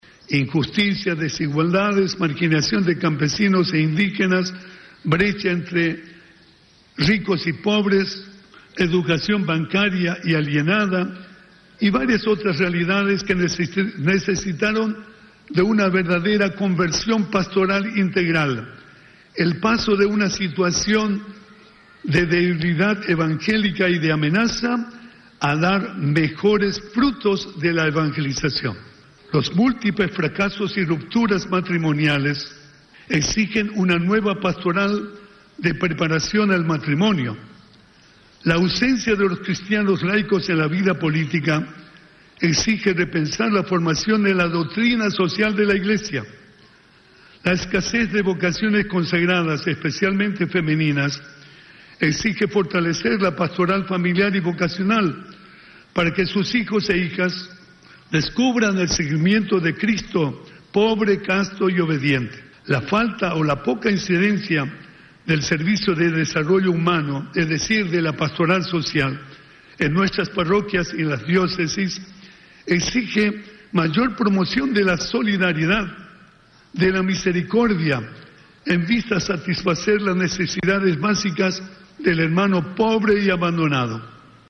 El arzobispo de Asunción Edmundo Valenzuela, en el penúltimo día del novenario de la Virgen de Caacupé, exhortó a las autoridades a reducir la brecha entre los ricos y los pobres.